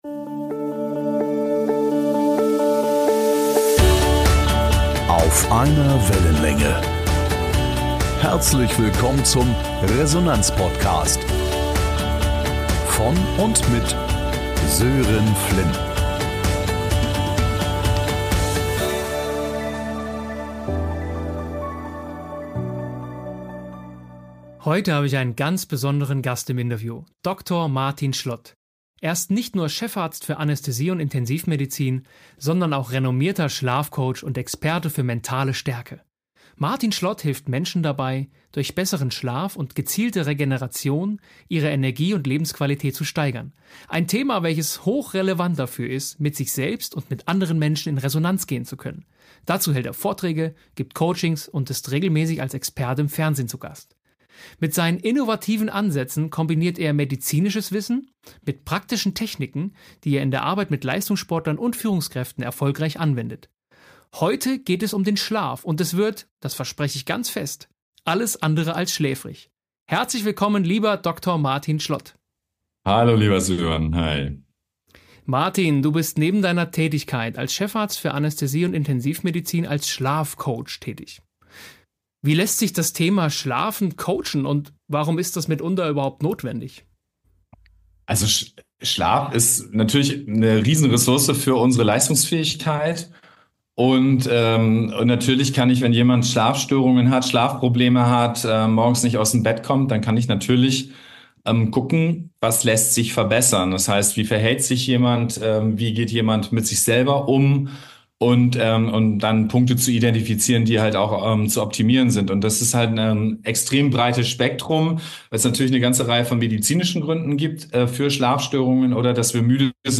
#033 Resonanzfaktor Schlaf | Interview